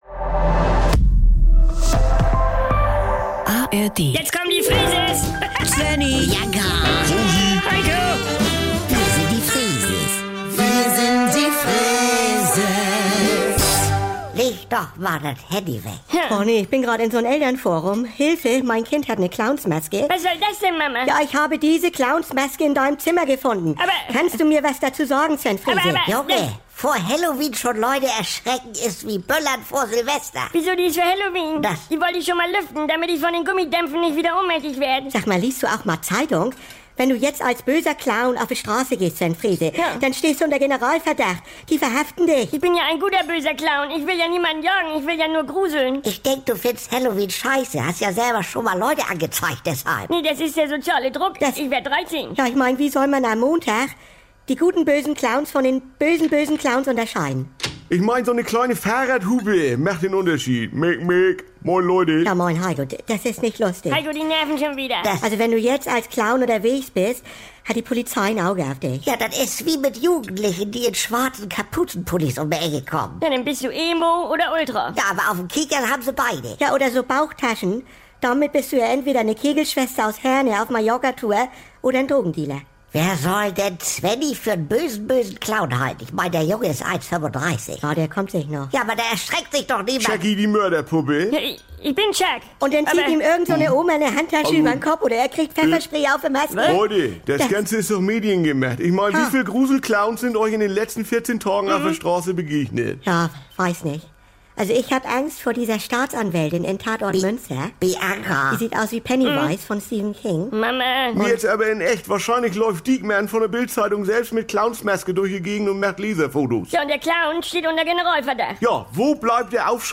"Wir sind die Freeses" um 7.17 Uhr als Best-Of - und jederzeit verfügbar in den NDR 2 Comedy Highlights.